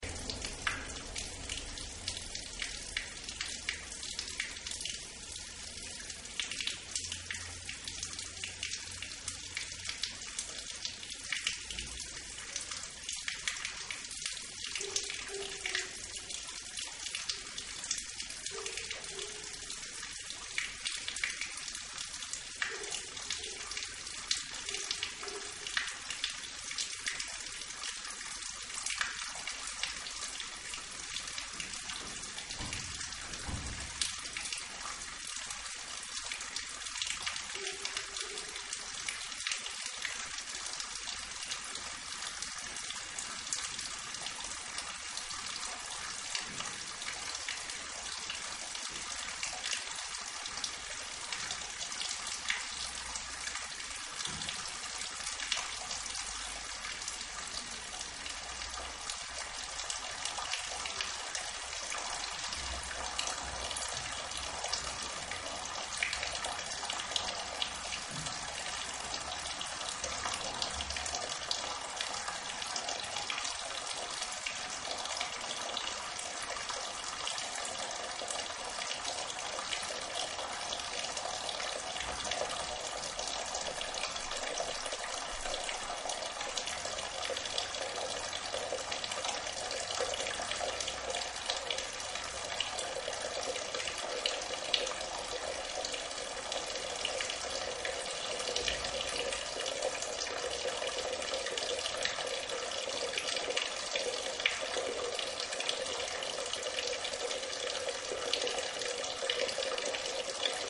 The sound of water in the Carvajales palace
El elemento decorativo más significativo es el mascarón central por donde cae el agua.
pilarzaguancentrodocumentacionmusica-iesalbayzin-.mp3